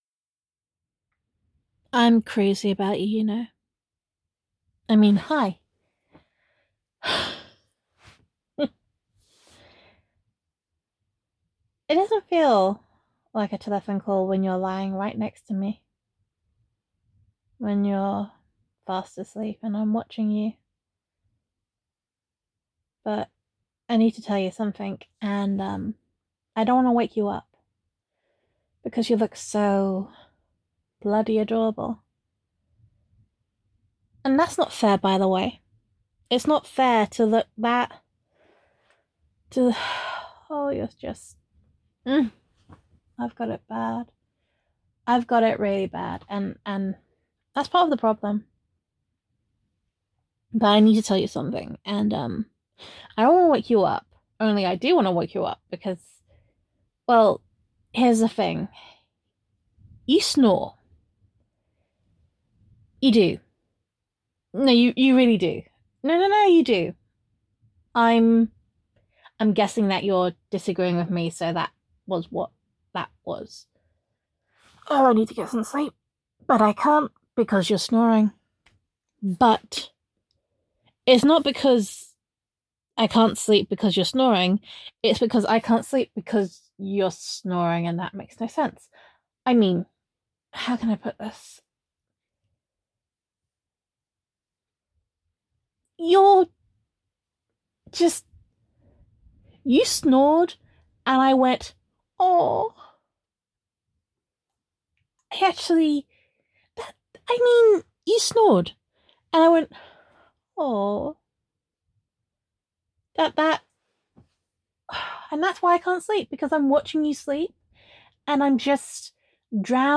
Same voicemail as posted earlier just without the telephone EQ.
[F4A] Crazy About You [Unfairly Adorable][You Snore][Love Addled Mess][Love Confession][Telephone EQ][Gender Neutral][Girlfriend Voicemail]